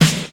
• Studio Acoustic Snare Sound D# Key 170.wav
Royality free snare one shot tuned to the D# note. Loudest frequency: 2132Hz
studio-acoustic-snare-sound-d-sharp-key-170-AD1.wav